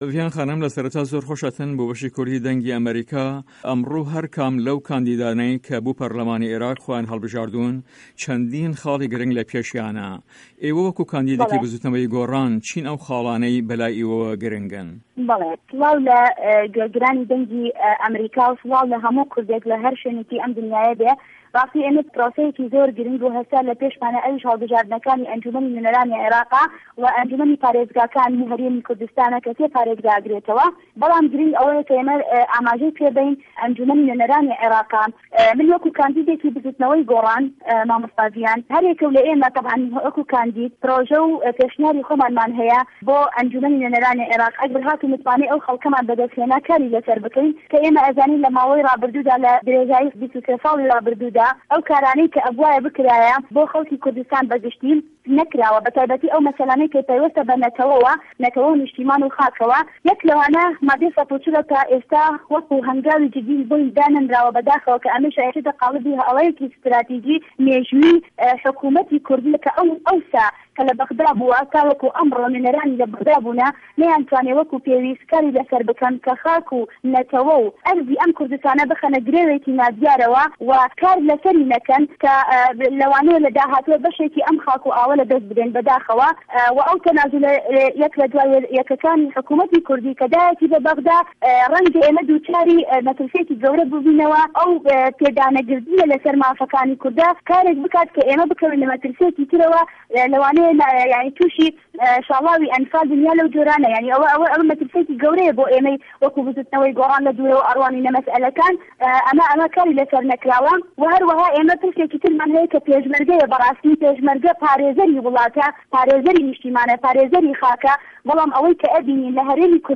هه‌رێمه‌ کوردیـیه‌کان - گفتوگۆکان
له‌ هه‌ڤپه‌ێڤینێکدا له‌گه‌ڵ ته‌له‌/ رادێۆێ به‌شی کوردی ده‌نگی ئه‌مه‌ریکا